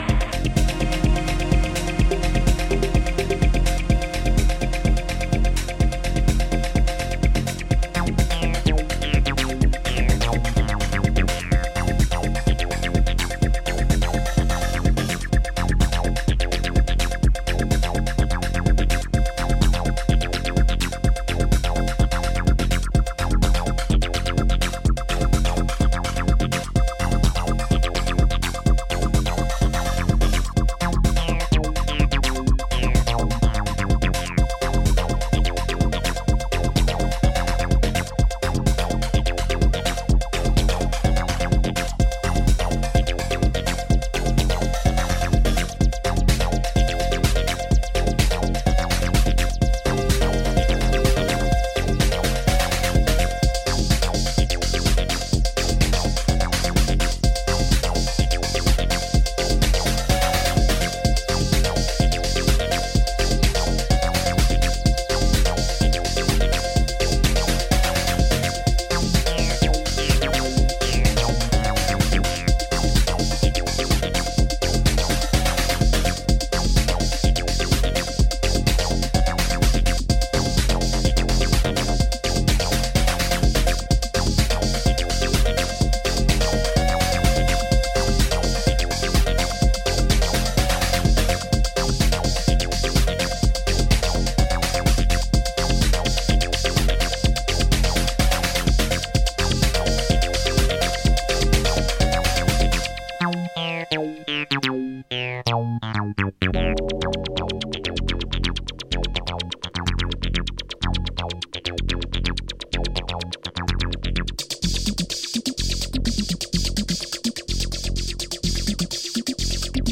Used Acid House